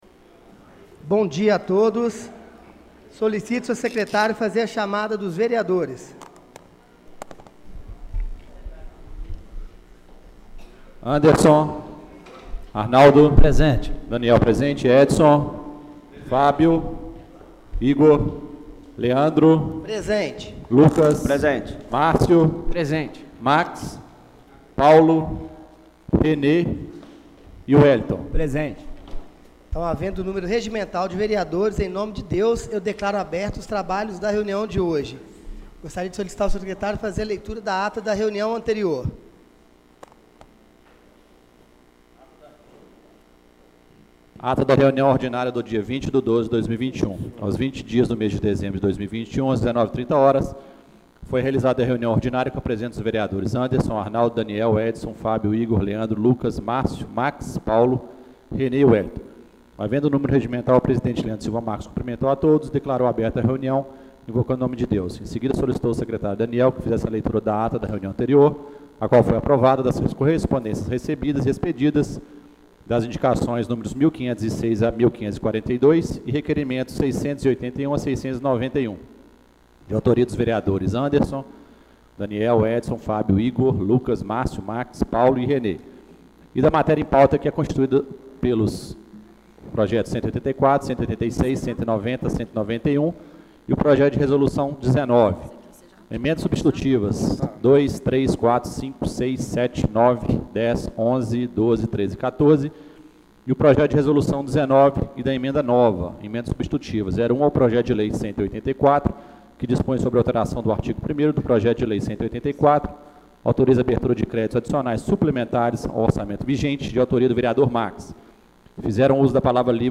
Sessão Solene de Posse do dia 03/01/2022